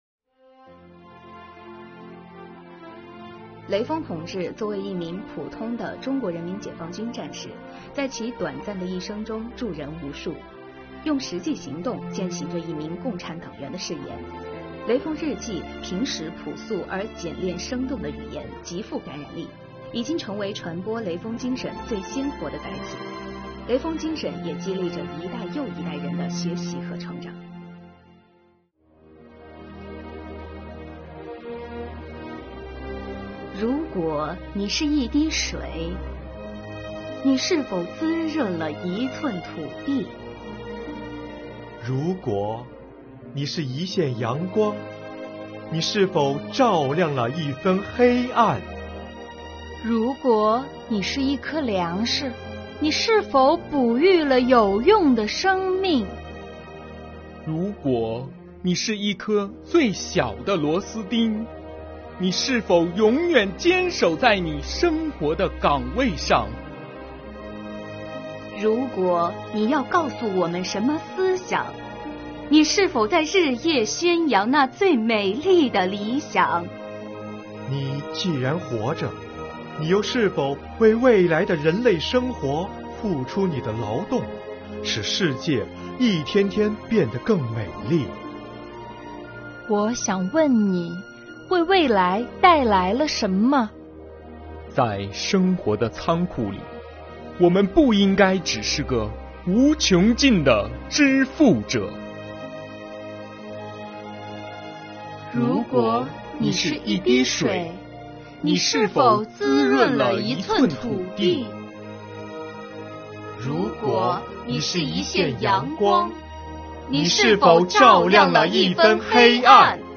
诵读征集活动优秀作品